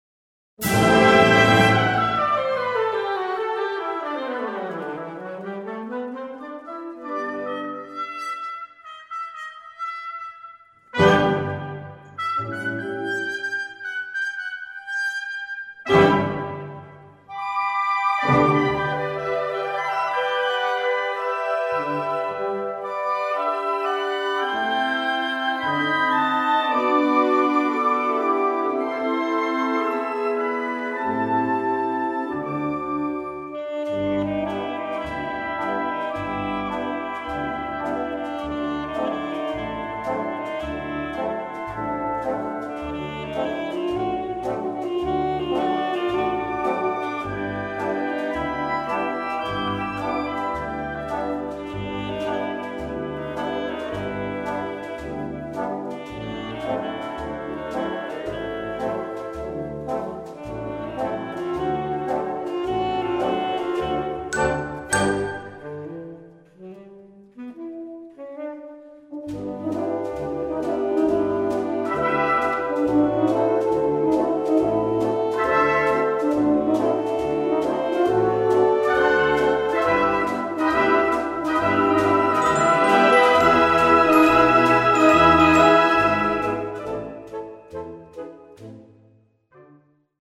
Gattung: Evergreen
A4 Besetzung: Blasorchester Zu hören auf